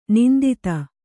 ♪ nindita